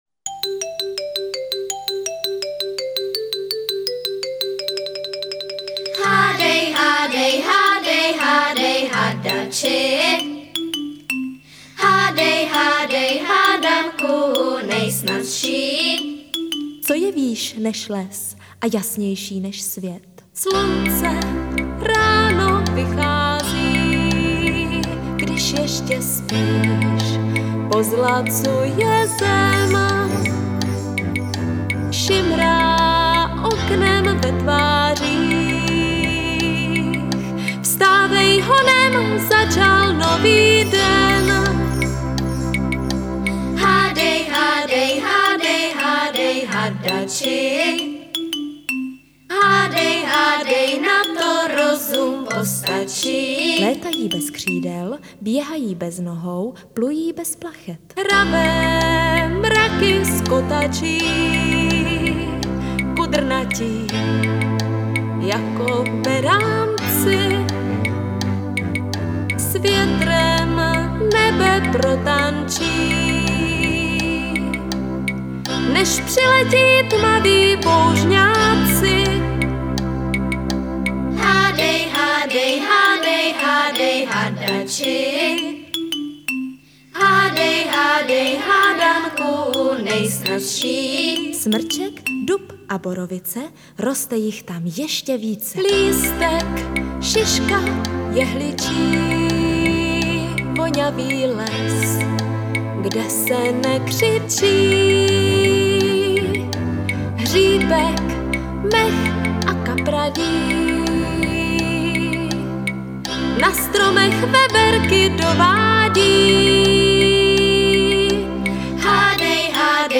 Dětská písnička